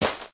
sounds / material / human / step / t_gravel2.ogg
t_gravel2.ogg